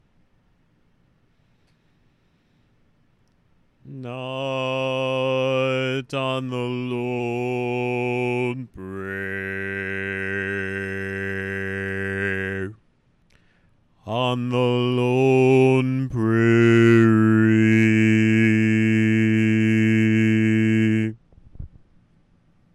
How many parts: 4
Type: Barbershop
Each recording below is single part only.